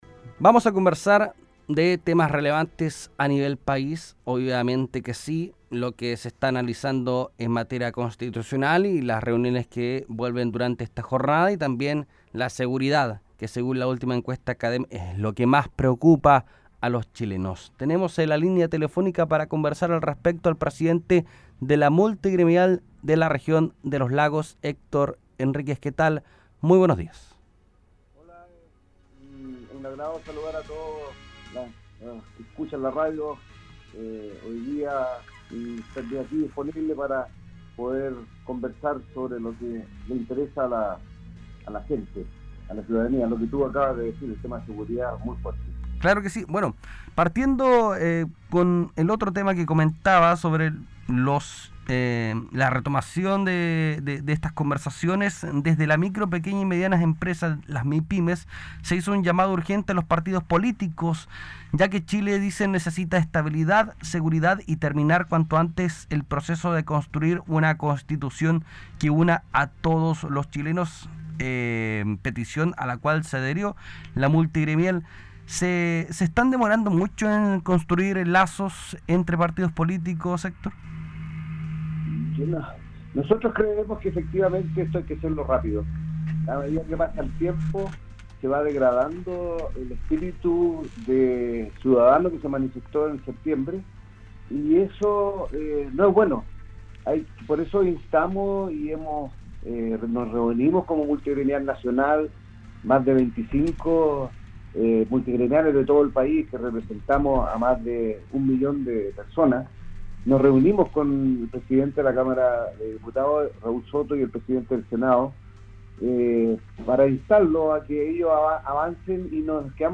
La Multigremial regional suscribe la idea de una sola Comisión y/o Convención constitucional de 50 integrantes, donde se vean representadas todas las regiones del país, como también representantes de las etnias. Escucha la entrevista completa: https